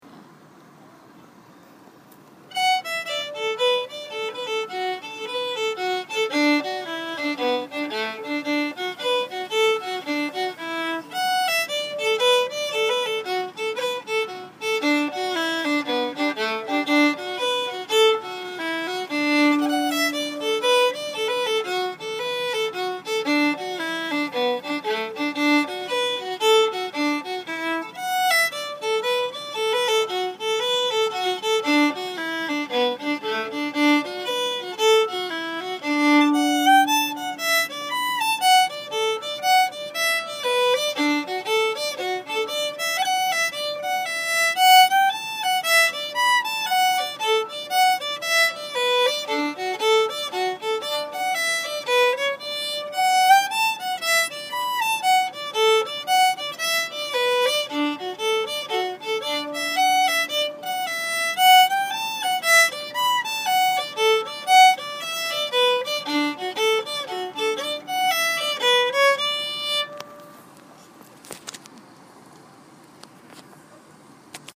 Kitty’s Wedding (2 part hornpipe in D)